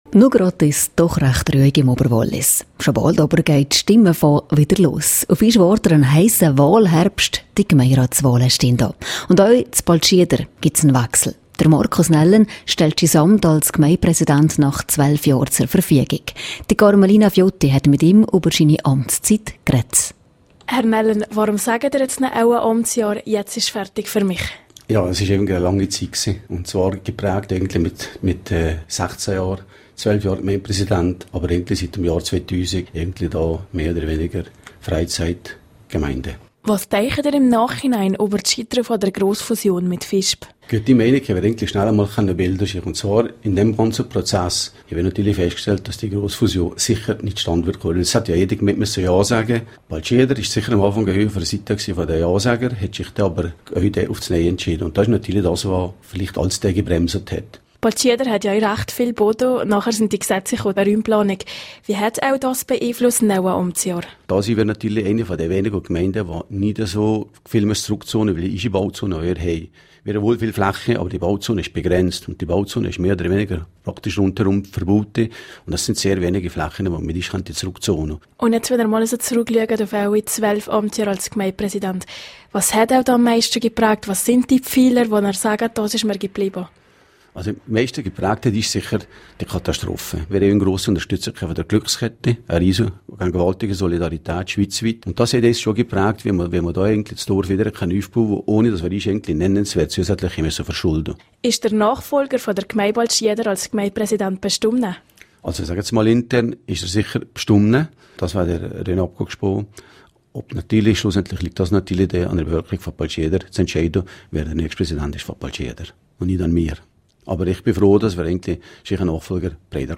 Doch schlussendlich entscheidet das Volk, wer das Zepter von Baltschieder übernehmen wird./vc Interview mit Markus Nellen (Quelle: rro)